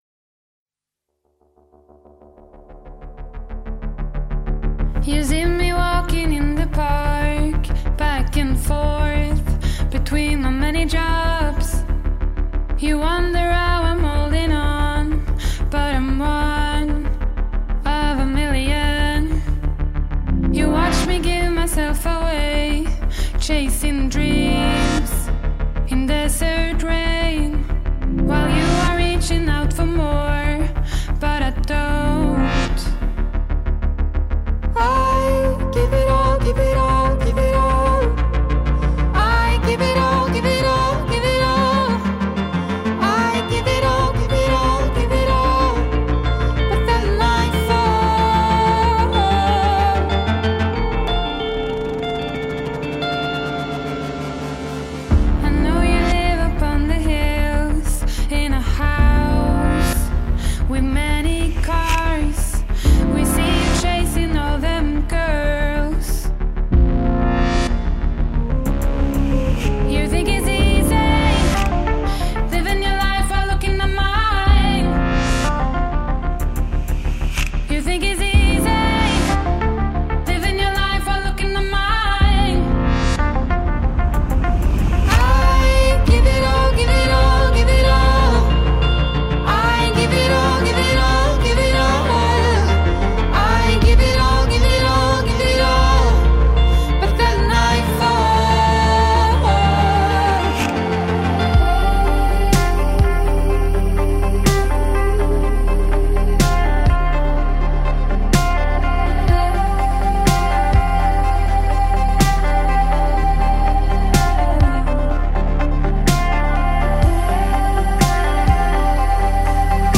electronic indie pop band